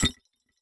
Futuristic Sounds (23).wav